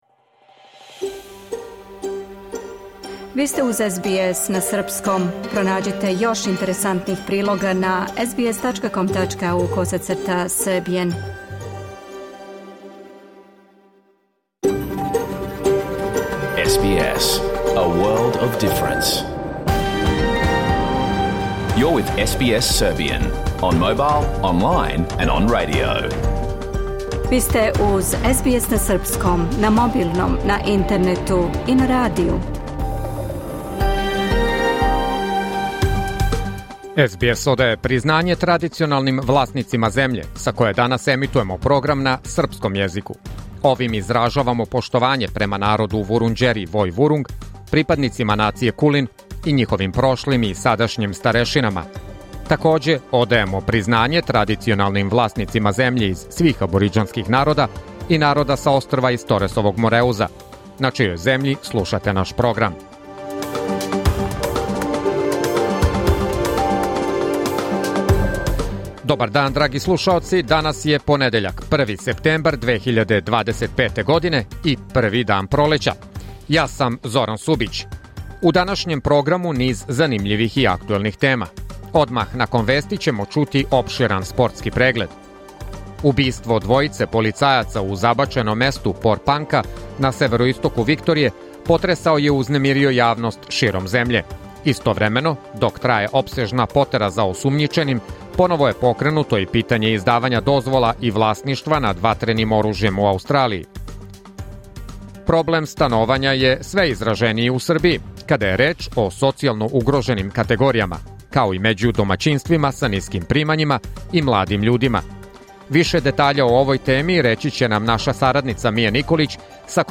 Програм емитован уживо 1. септембра 2025. године